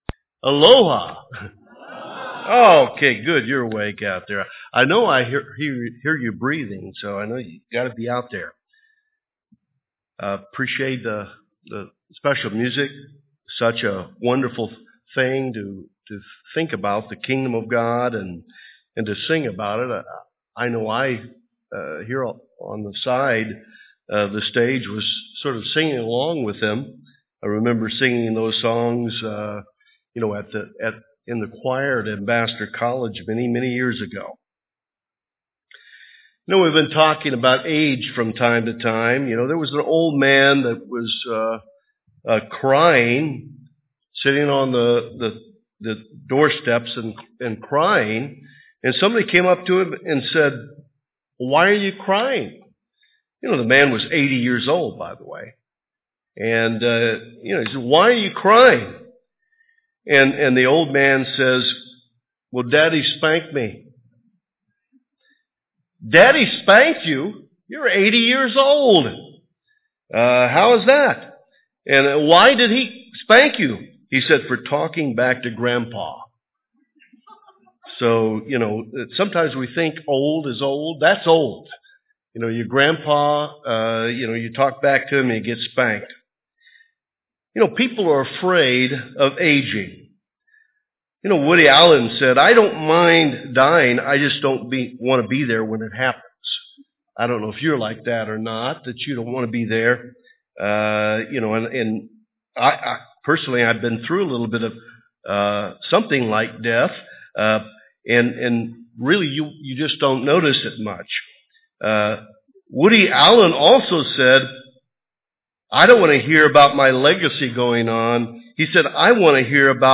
This sermon was given at the Maui, Hawaii 2013 Feast site.